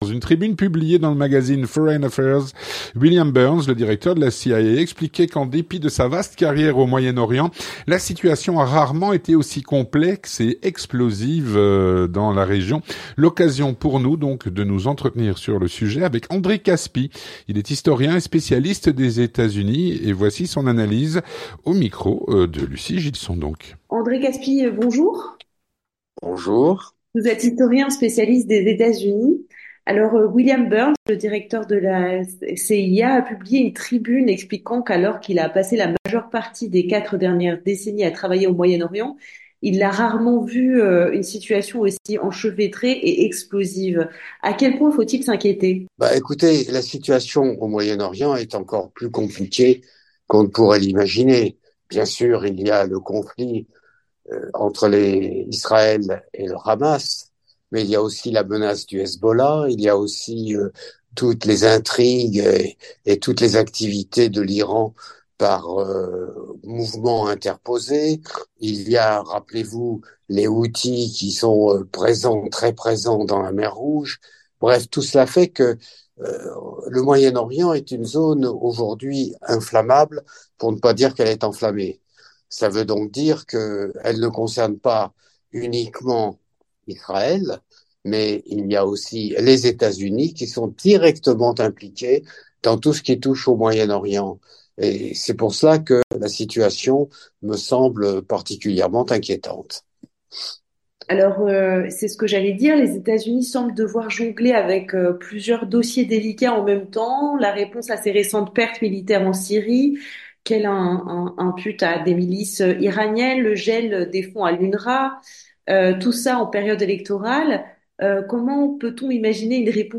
L'entretien du 18H - Au Moyen-Orient, la situation a rarement été aussi complexe et explosive. Avec André Kaspi (02/02/2024)
Avec André Kaspi, historien et spécialiste des Etats-Unis.